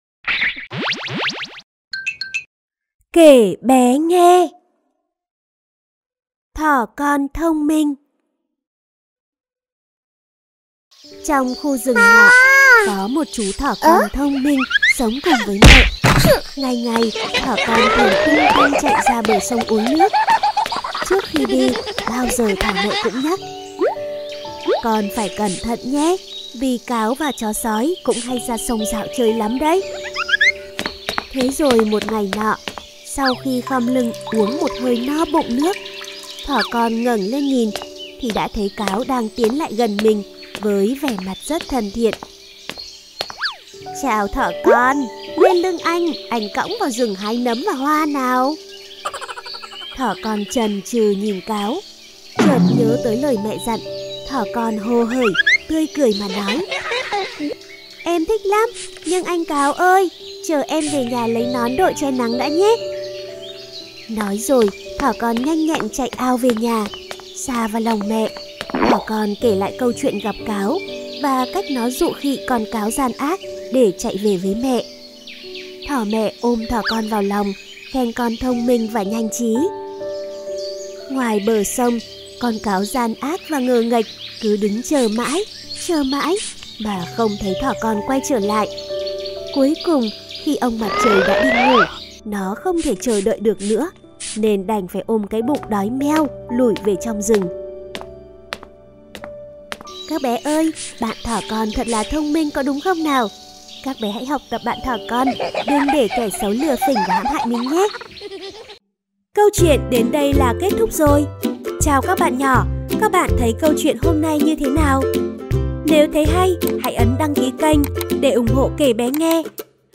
Sách nói | Thỏ Con Thông Minh